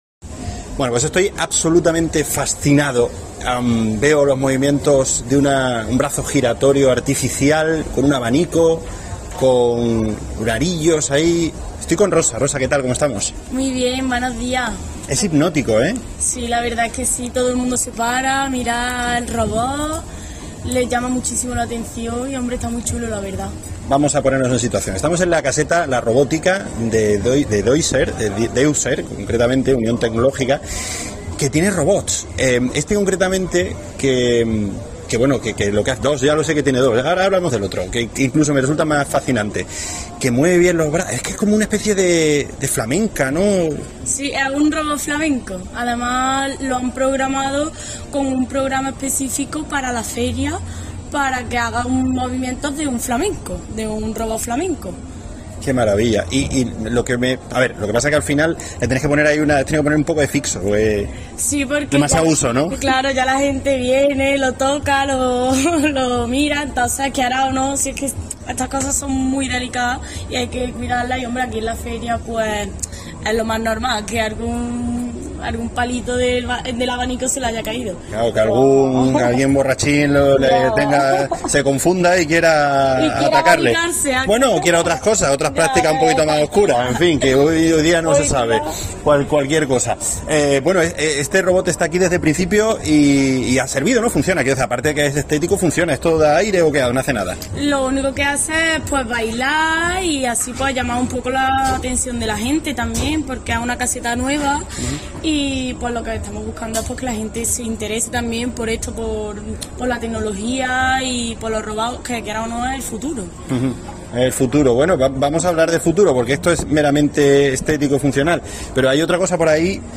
En la caseta La Robótica, de Deuser, una máquina sirve bebidas y otra baila para solaz de propios y extraños y como un canto a la modernidad